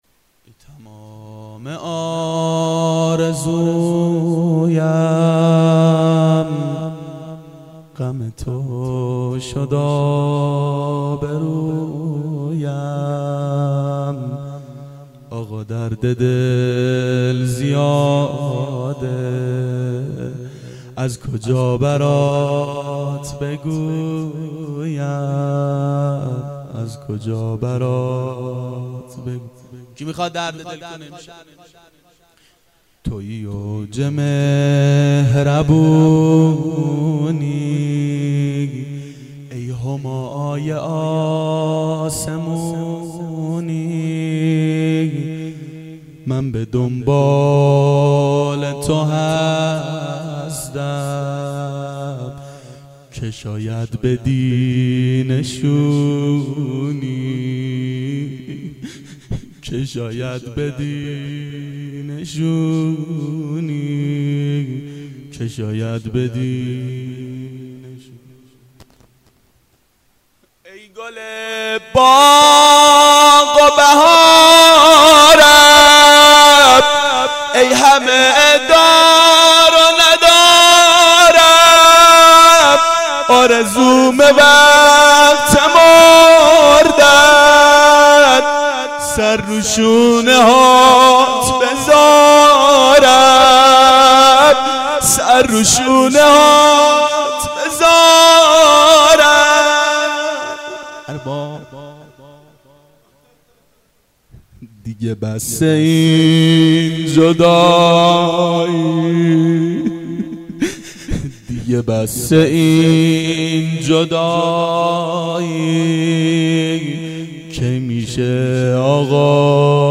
شام غریبان حضرت زهرا سلام الله علیها 89 هیئت محبان امام حسین علیه السلام شهر اژیه